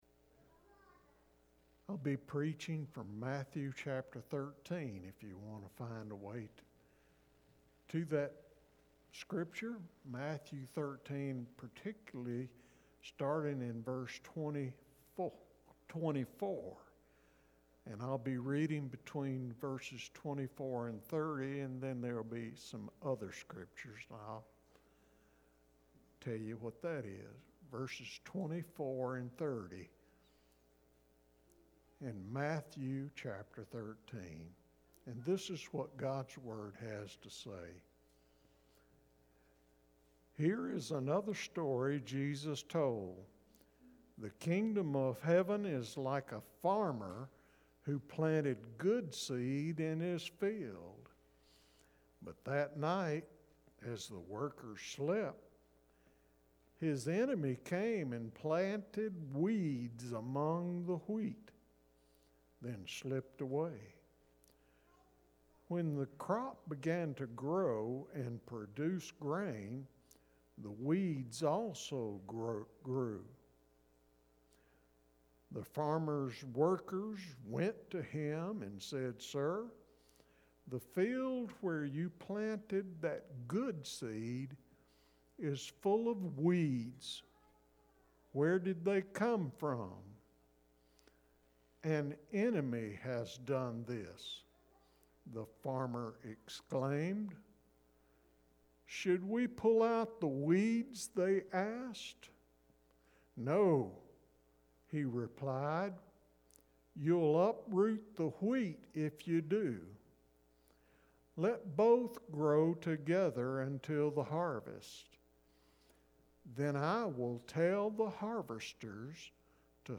Service Type: Sermon only